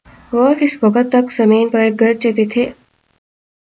Spoofed_TTS/Speaker_12/16.wav · CSALT/deepfake_detection_dataset_urdu at main